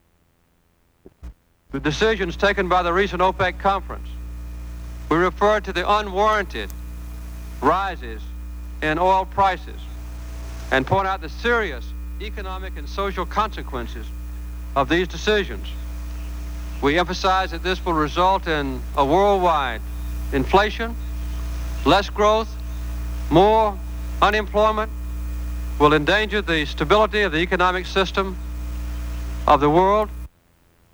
Press Conference in Tokyo